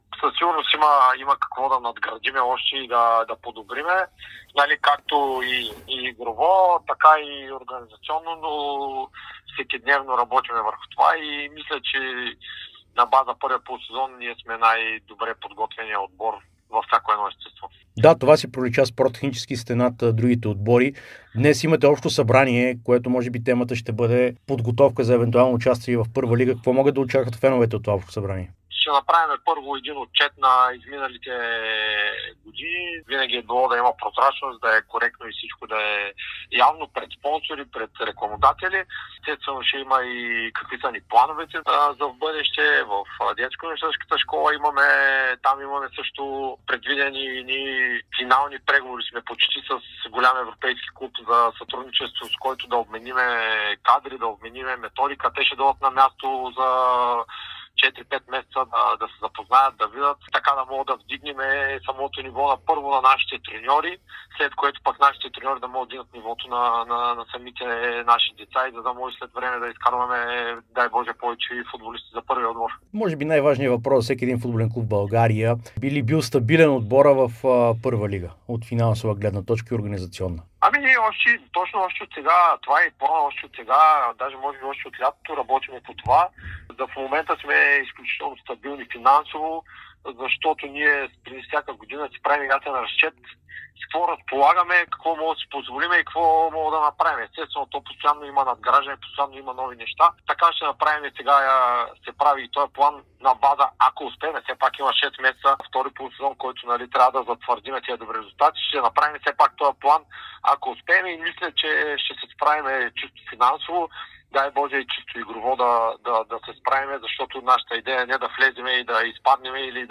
специално интервю за Дарик радио